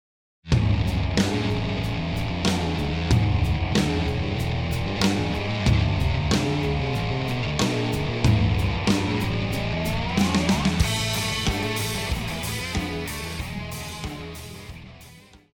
套鼓(架子鼓)
乐团
演奏曲
流行音乐,另类摇滚
独奏与伴奏
有主奏
有节拍器